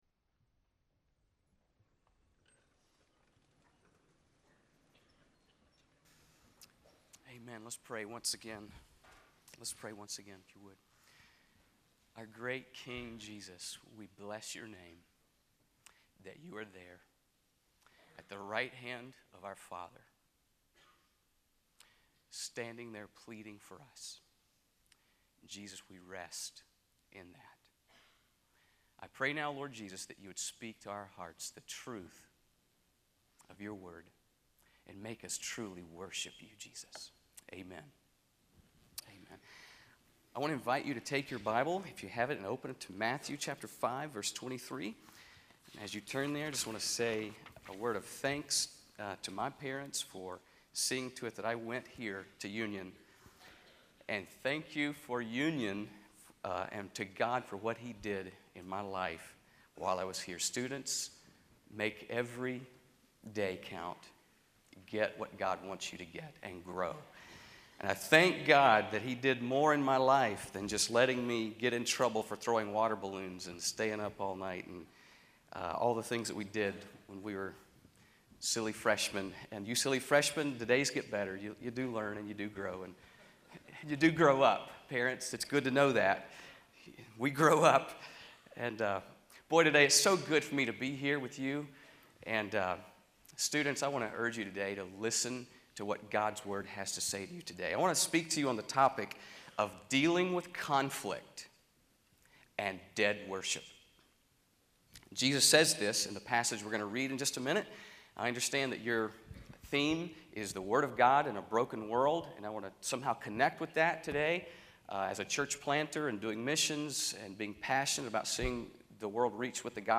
Union University, a Christian College in Tennessee
Chapels